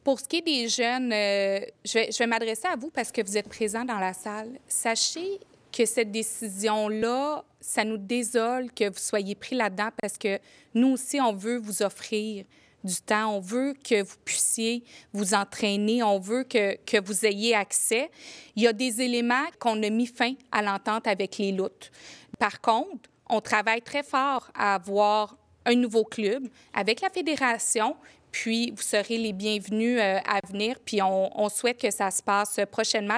Lors de la séance du conseil de Ville de Granby lundi soir, plusieurs parents et athlètes du défunt club de natation Les Loutres étaient présents pour remettre une pétition, demandant à la Ville de revenir sur sa décision ou à tout le moins de trouver une solution pour permettre à leurs enfants de profiter des installations du Centre aquatique Desjardins.
Pour sa part, la mairesse de Granby, Julie Bourdon, tenait à s’adresser également aux nageurs comme aux parents.